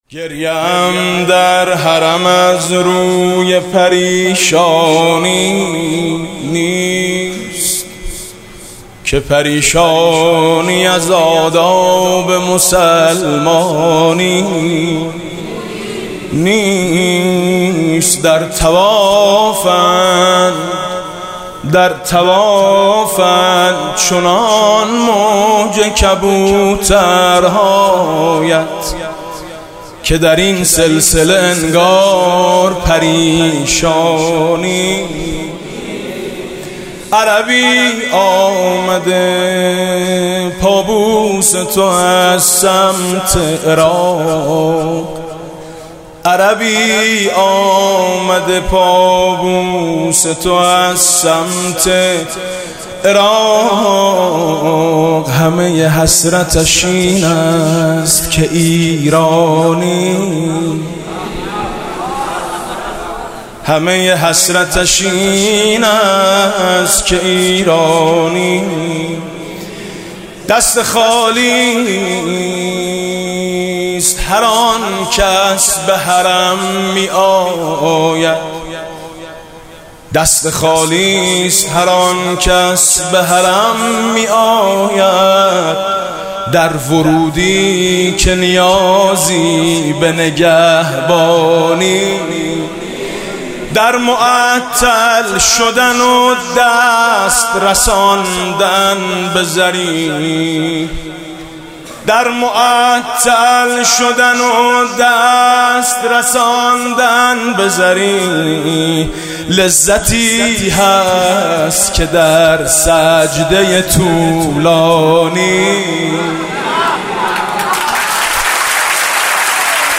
«میلاد امام رضا 1393» مدح: مادر از باب الرضا رد شد به من رو کرد و گفت